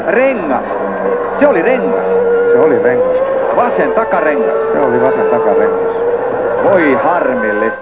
rengas.wav